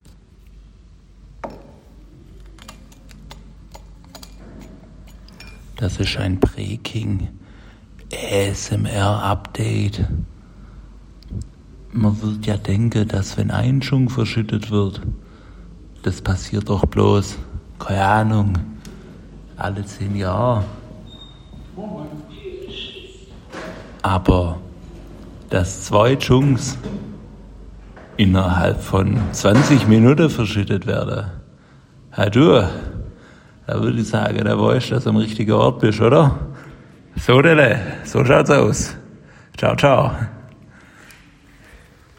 Der erschte schwäbische ASMR-Podcascht